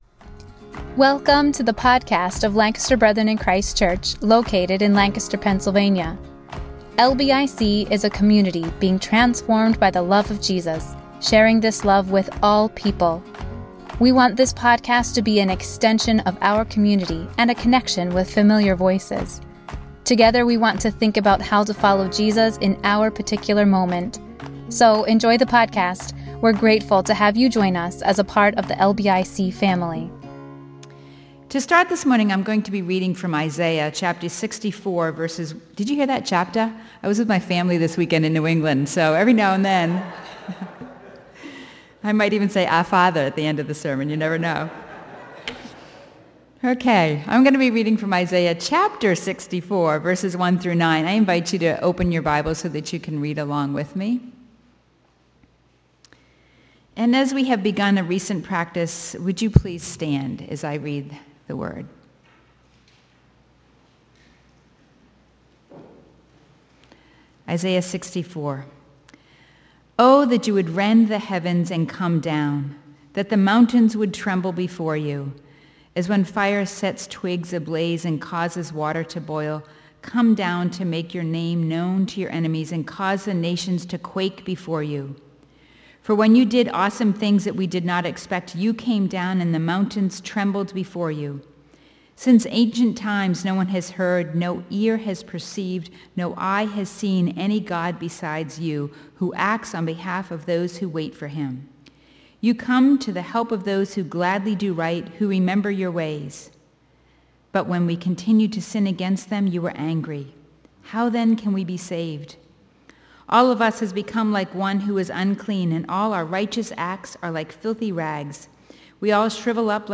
A message from the series "Advent."